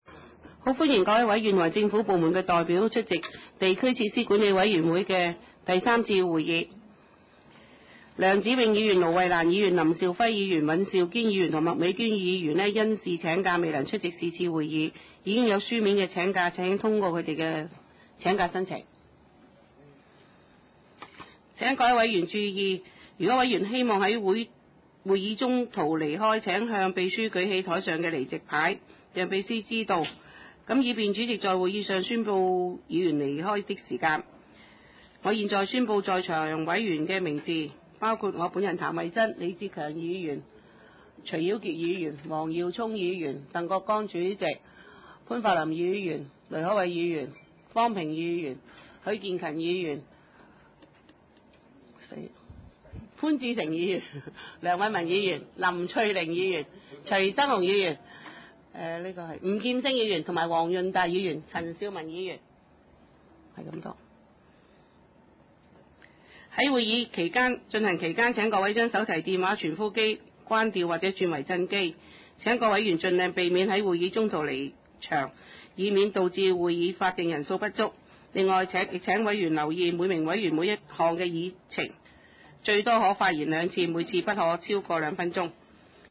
葵青民政事務處會議室
開會詞